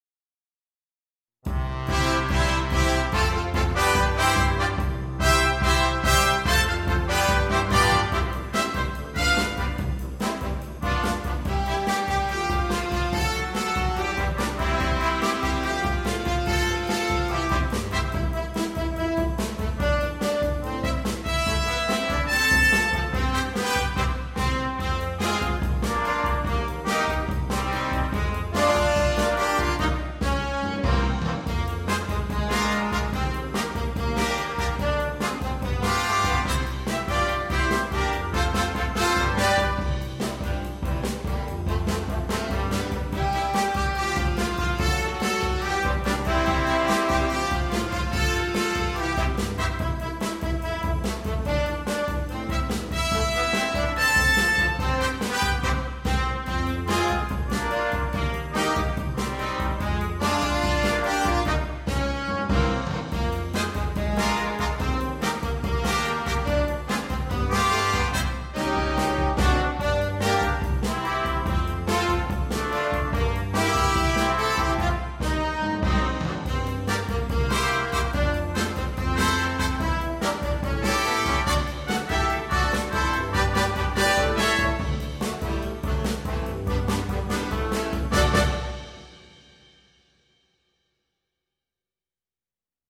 для джаз-бэнда